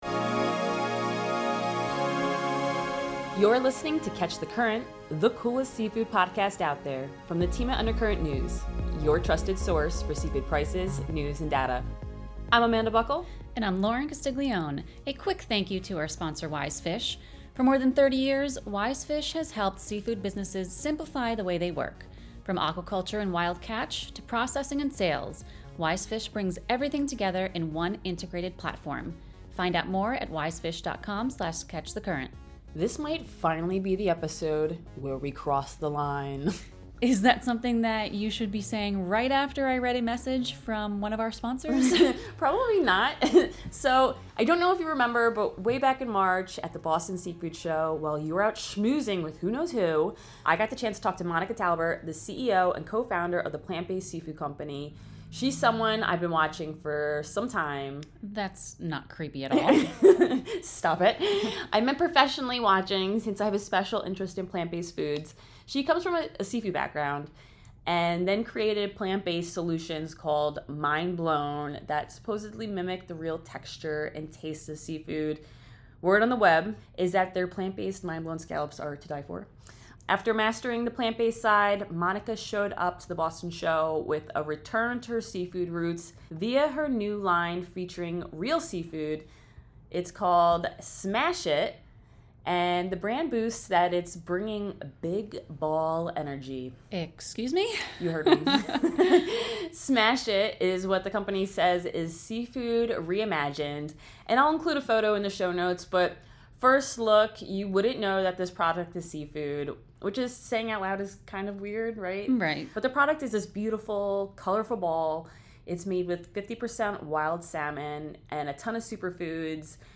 Tune in for exclusive interviews, on-the-ground recordings from seafood events around the globe, and the lively banter you’ve come to expect from your favorite seasoned seafood specialists.